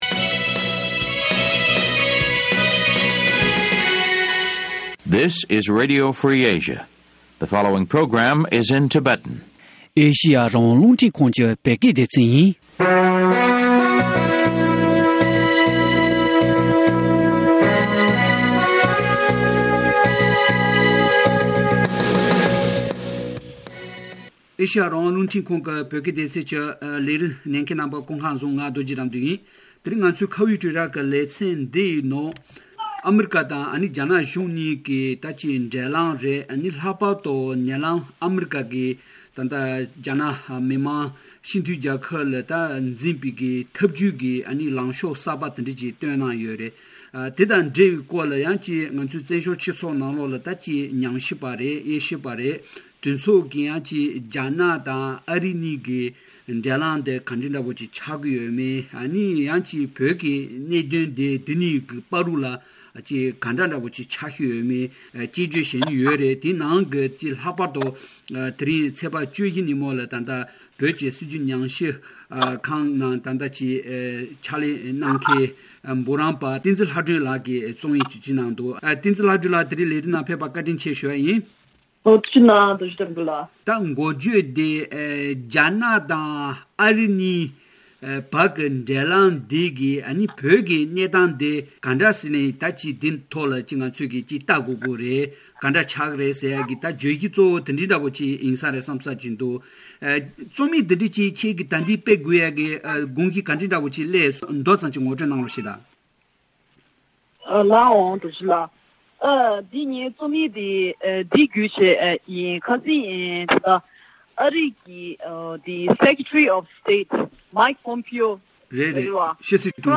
གླེང་མོ་བྱེད་པ་ཉན་རོགས